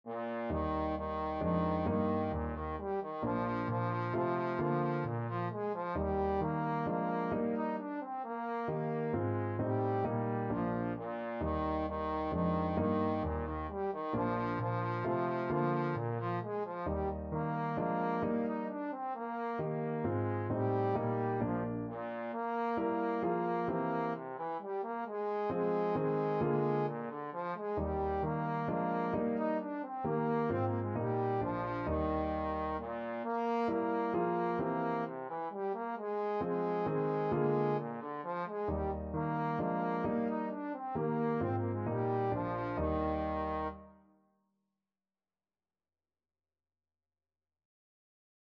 = 132 Allegro (View more music marked Allegro)
Bb3-Eb5
3/4 (View more 3/4 Music)
Classical (View more Classical Trombone Music)